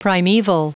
Prononciation du mot primeval en anglais (fichier audio)
Prononciation du mot : primeval